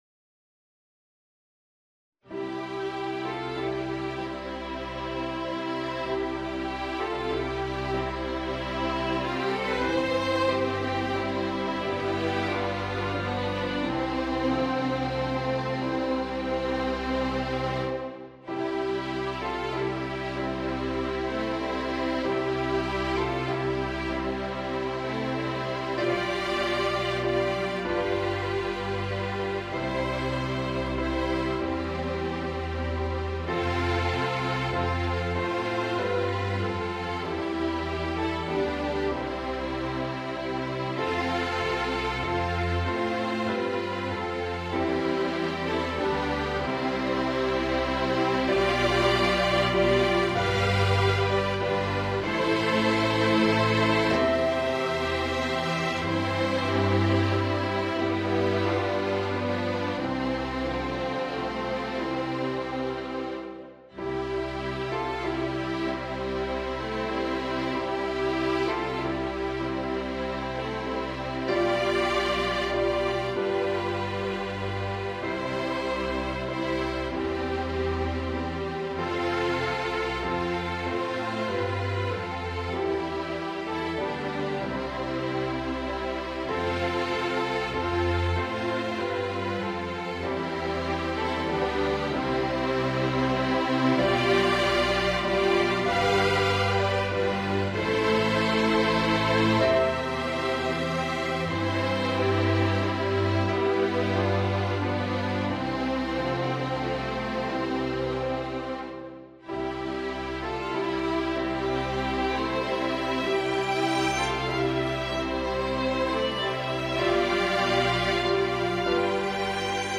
Grade = 3 Duration = 2:35 mins (3 verses)
The MP3 was recorded with NotePerformer 3.